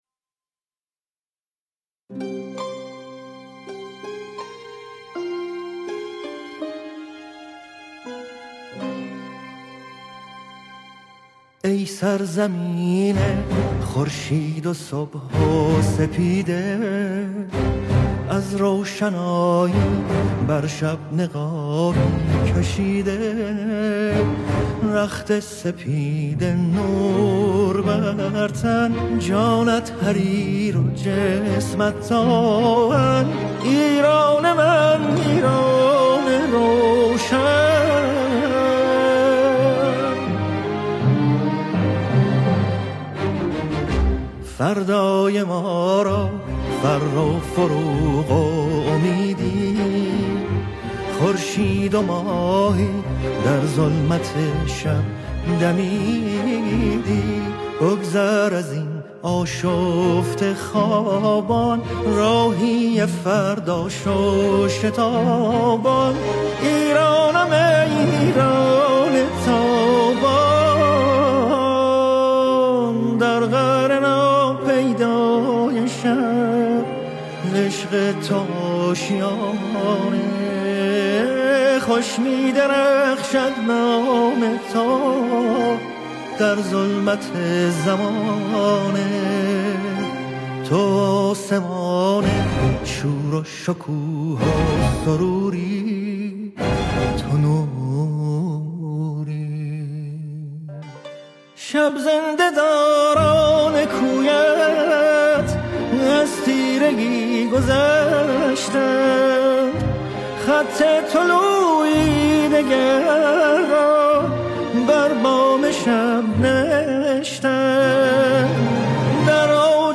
مثل همیشه با صدای جادویی خودش میبرتمون به جایی که باید.